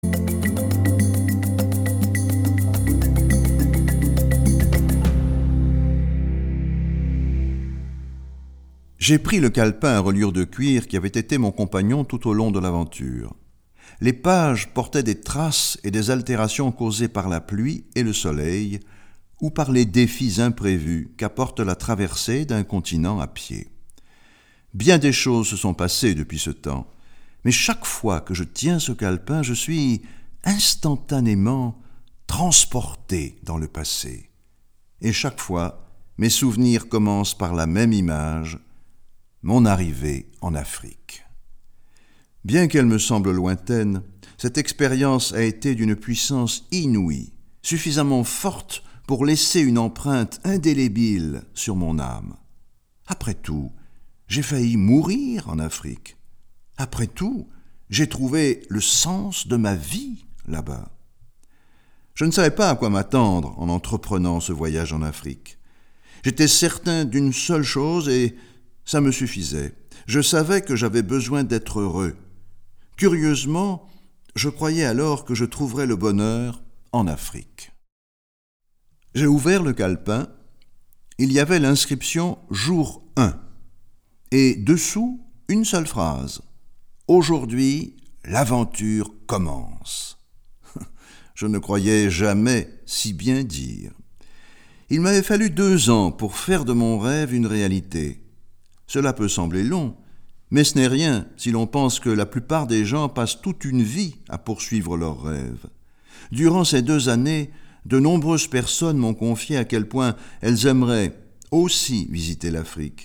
Ce petit livre audio surprendra et inspirera les auditeurs qui, dans la foulée du personnage principal, découvrirons l’étonnant potentiel en eux.